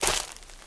High Quality Footsteps
STEPS Leaves, Walk 03.wav